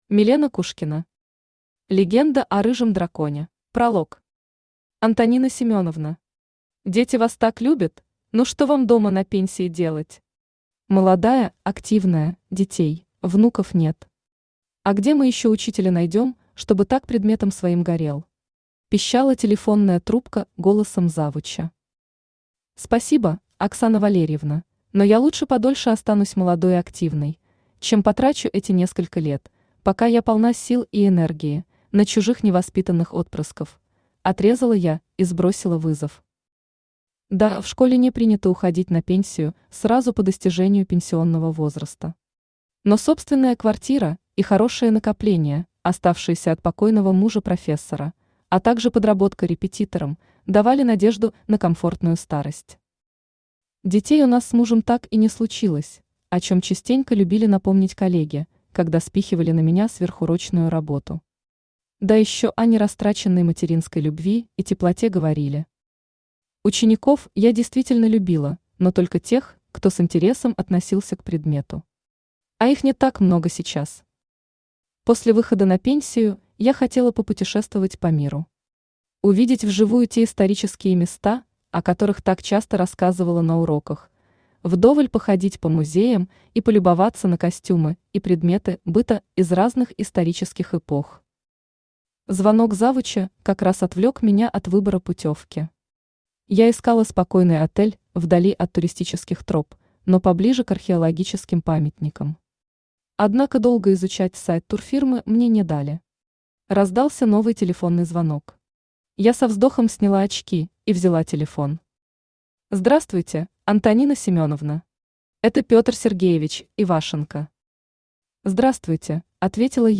Аудиокнига Легенда о рыжем драконе | Библиотека аудиокниг
Aудиокнига Легенда о рыжем драконе Автор Милена Кушкина Читает аудиокнигу Авточтец ЛитРес.